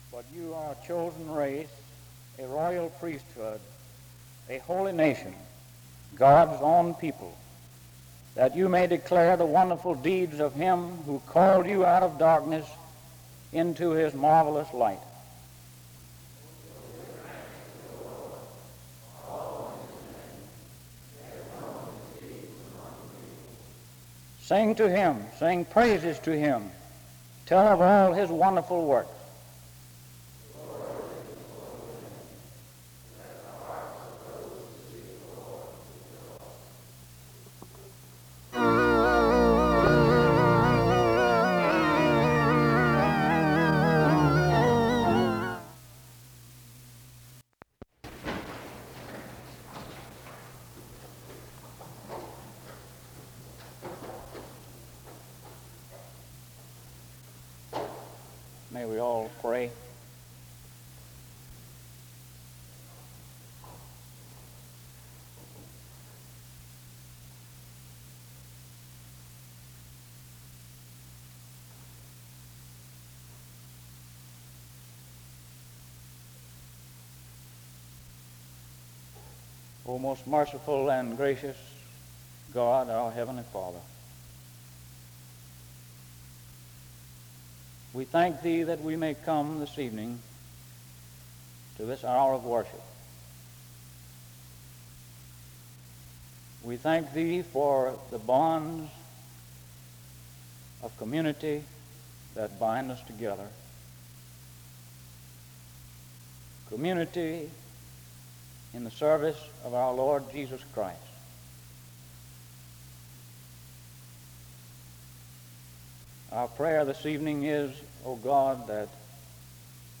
The service opens with an opening scripture reading from 0:00-0:37. A prayer is offered from 0:57-3:51. Music plays from 4:00-8:38.